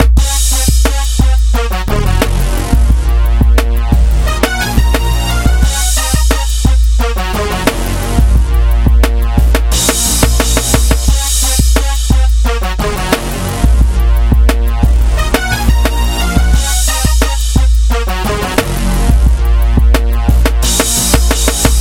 描述：灵魂/RB型轨道，G调，100bpm 预览是所有文件的粗略混合。
标签： 低音 铜管 吉他 钢琴 灵魂
声道立体声